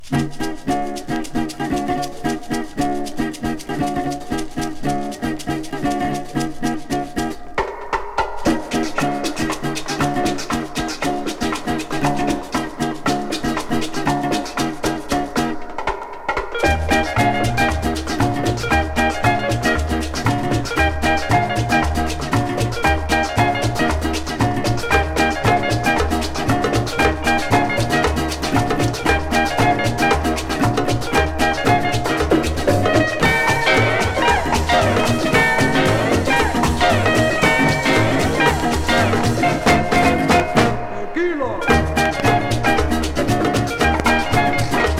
Jazz, Latin, Easy Listening　USA　12inchレコード　33rpm　Stereo